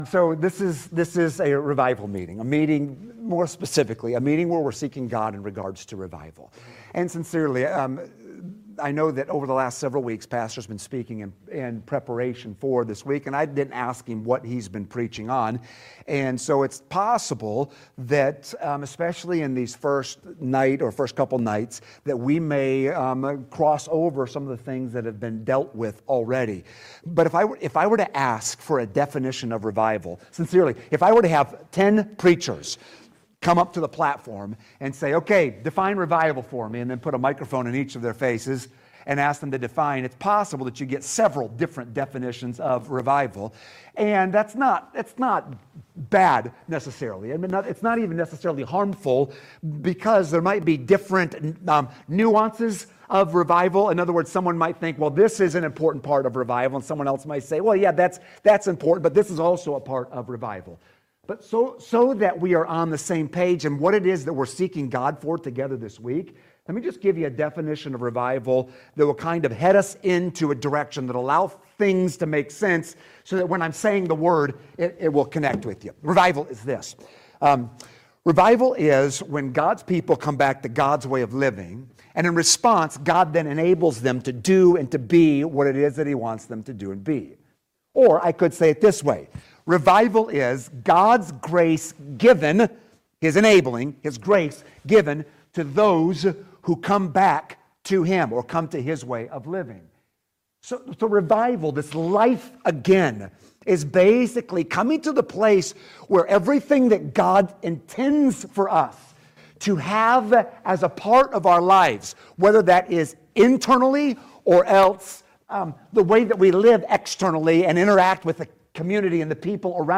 Neh. 8:1-9 Service Type: Midweek Service Topics